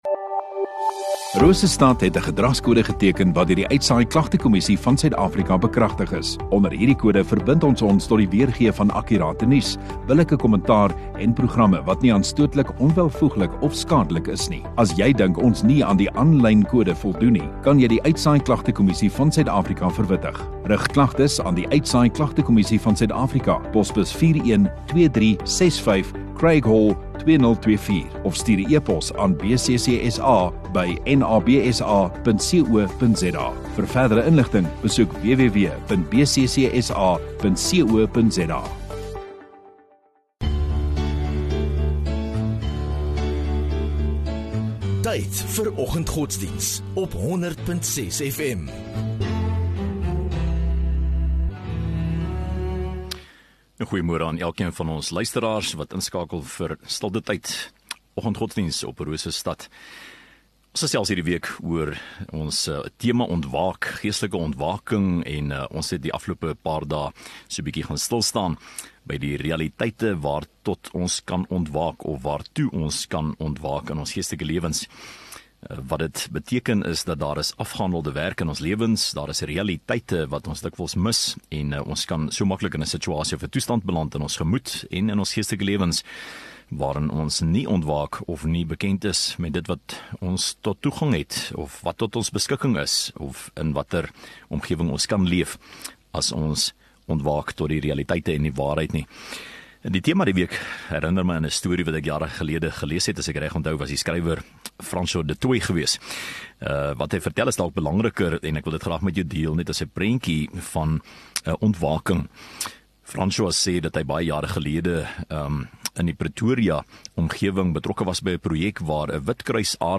14 Aug Donderdag Oggenddiens